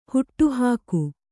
♪ huṭṭu hāku